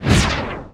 bullet_ice.wav